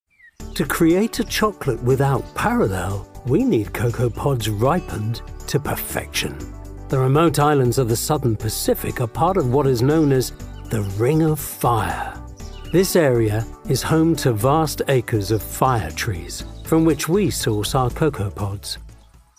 • Male
Showing: Promos & Idents Clips
Informative, Friendly, Gentle